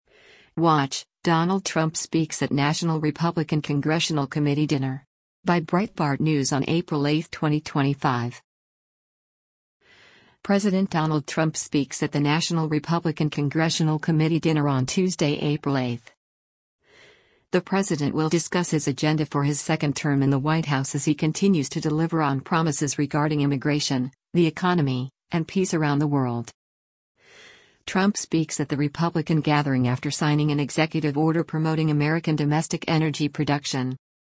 President Donald Trump speaks at the National Republican Congressional Committee dinner on Tuesday, April 8.